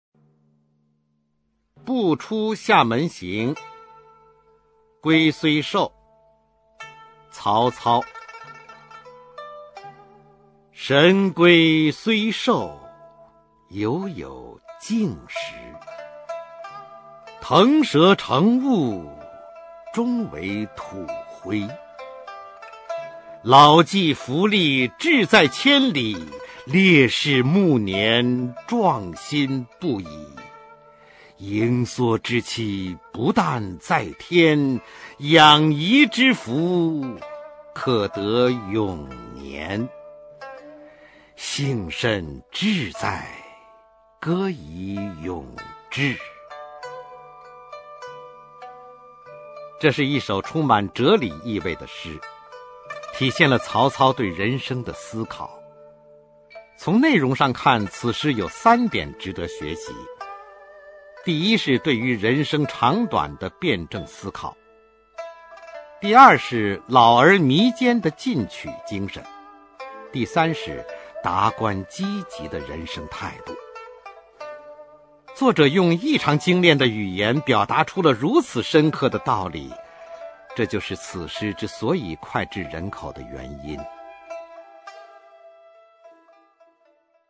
[魏晋诗词诵读]曹操-龟虽寿 古诗朗诵